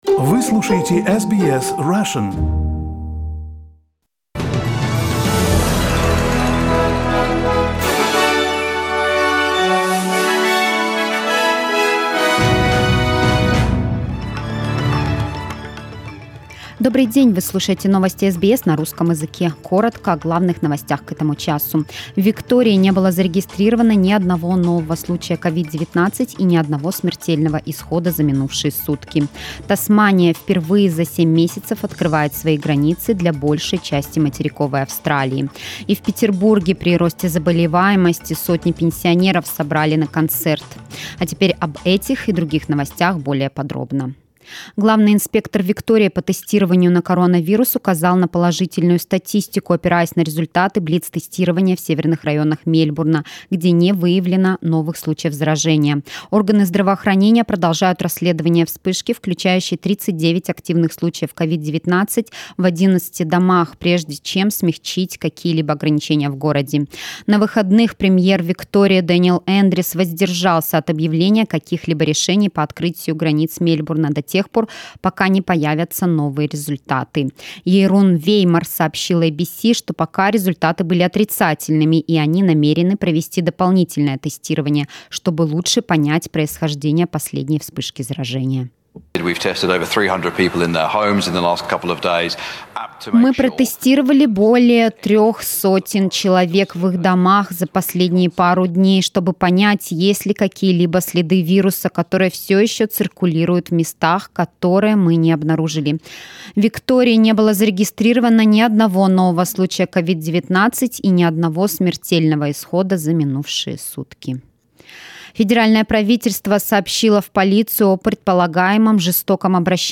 News bulletin October 26th